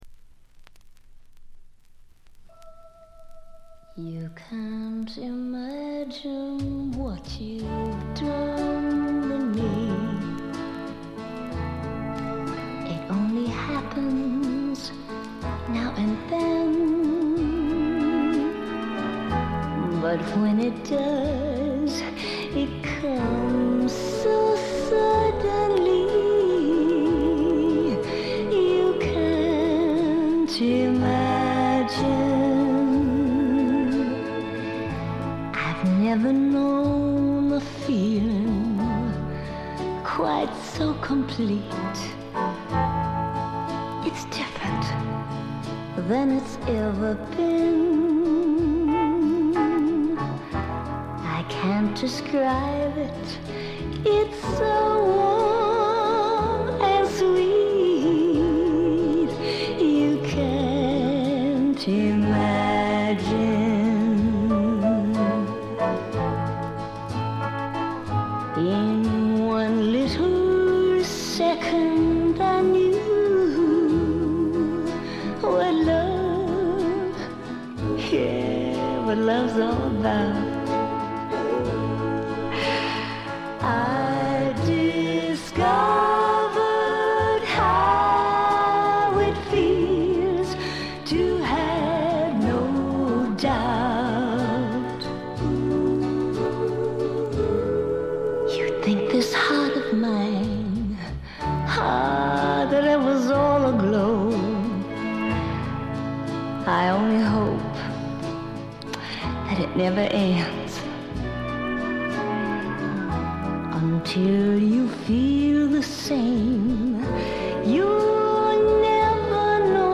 軽微なバックグラウンドノイズ。散発的なプツ音が2-3回程度。
試聴曲は現品からの取り込み音源です。